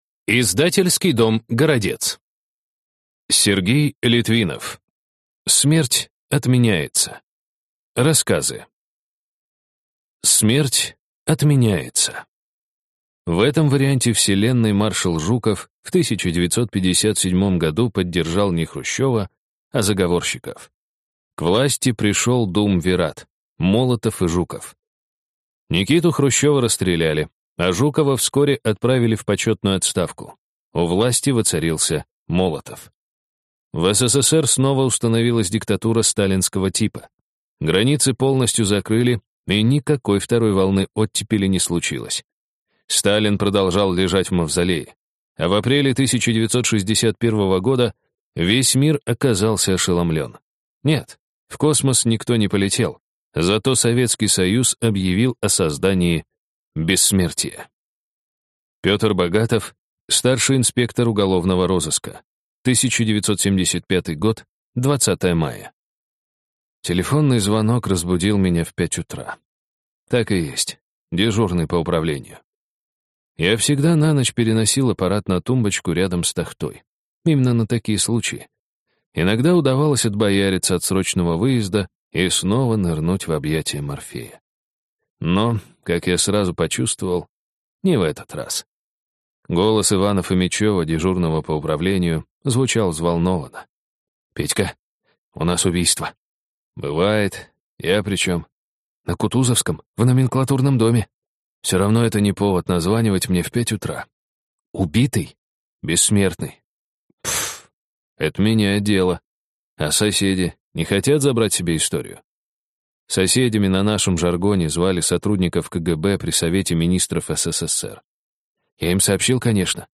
Аудиокнига Смерть отменяется | Библиотека аудиокниг